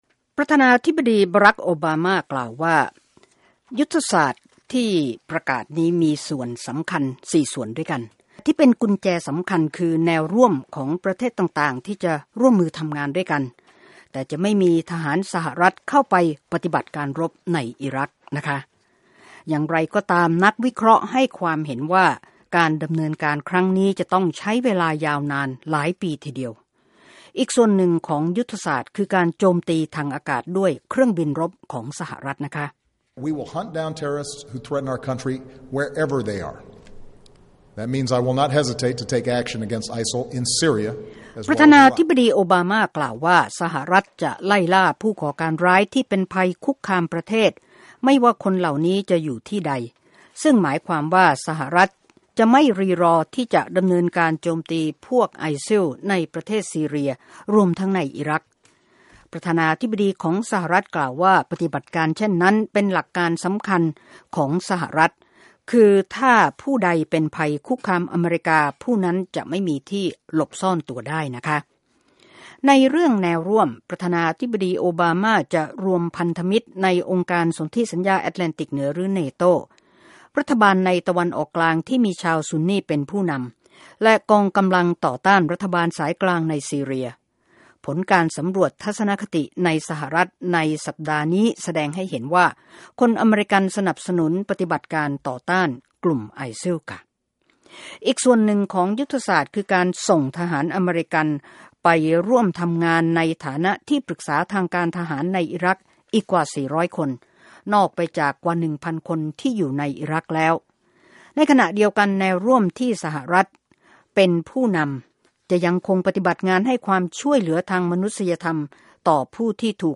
Obama Speech Islamic State